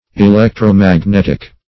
Electro-magnetic \E*lec`tro-mag*net"ic\, a.